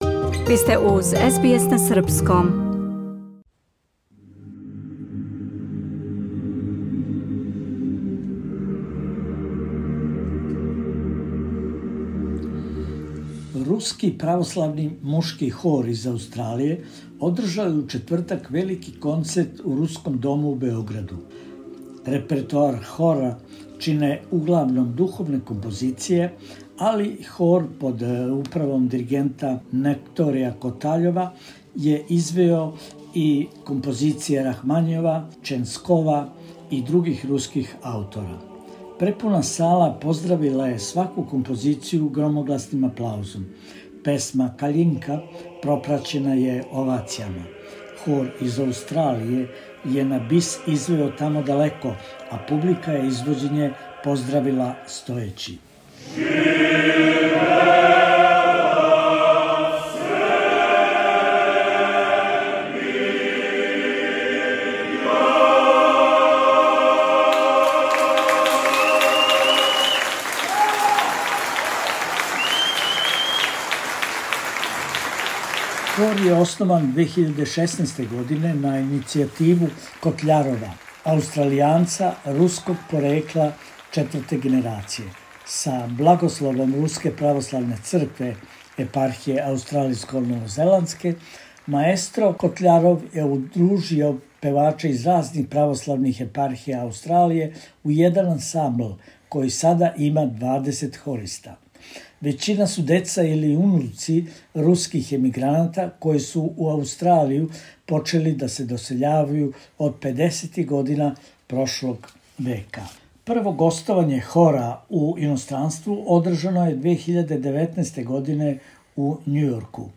ruski-hor.mp3